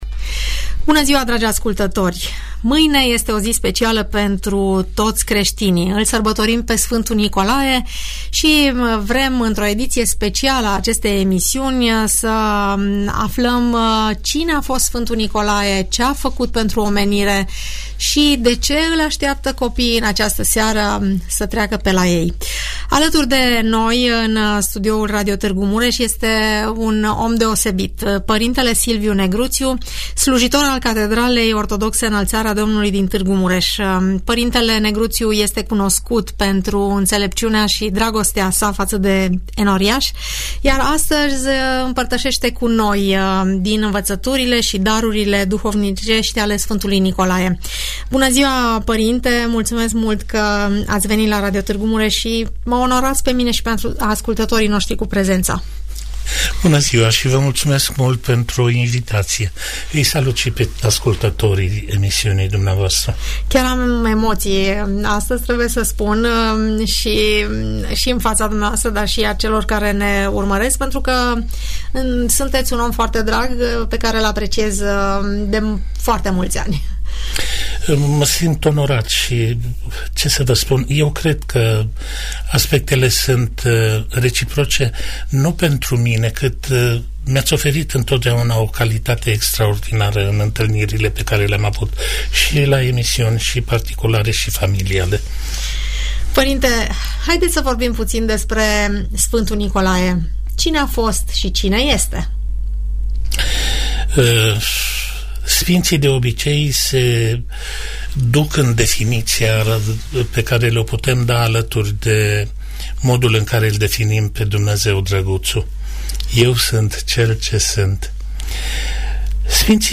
dialog luminos